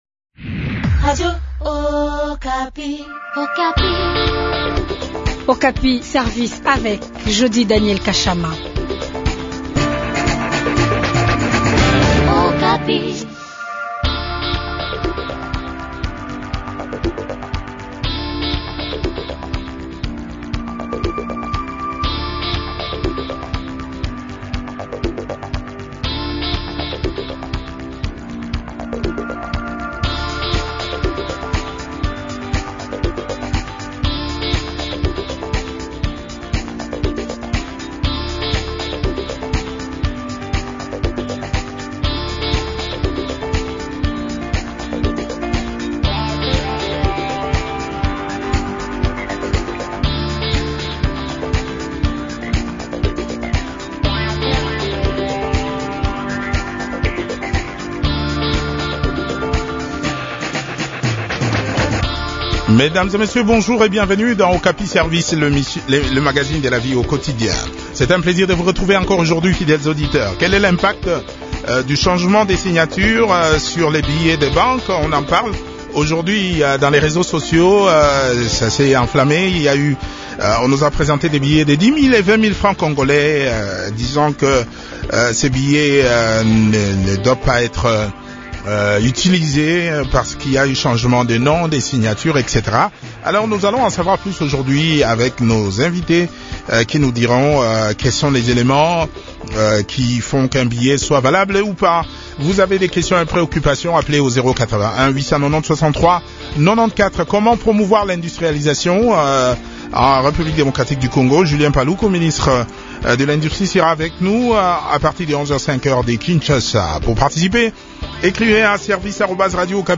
économiste et stratège.